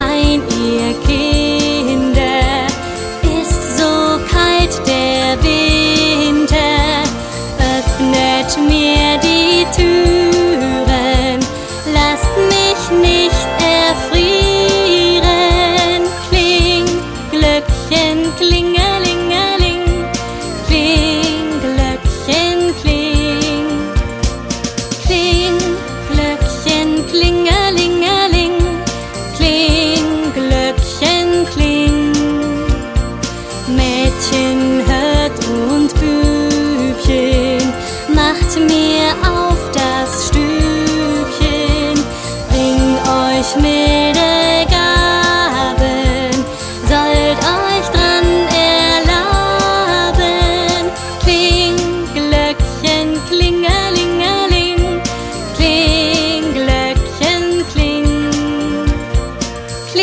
Melodie: Volksweise
Kinderlieder, vierstimmig